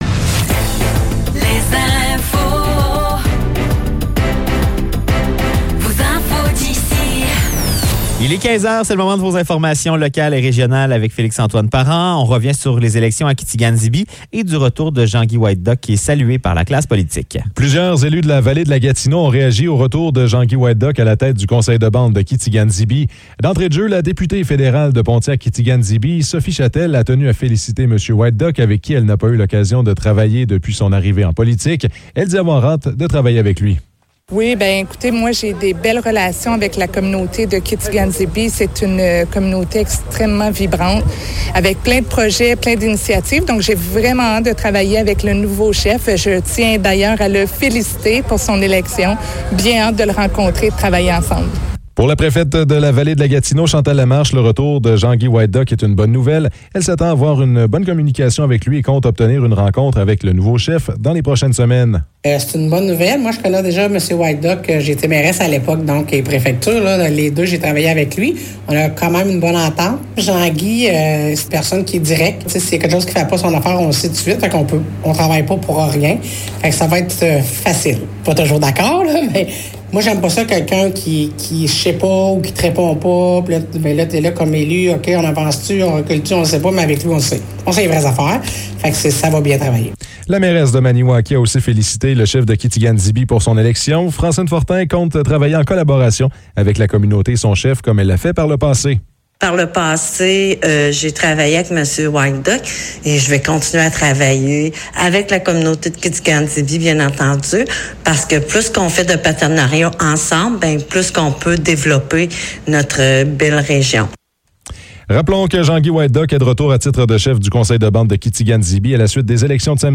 Nouvelles locales - 27 Août 2024 - 15 h